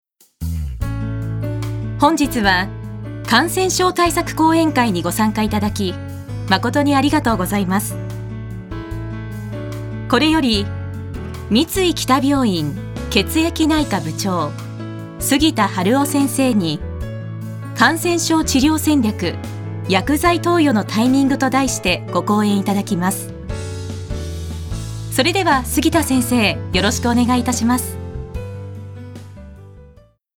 Voice／メゾソプラノ
ボイスサンプル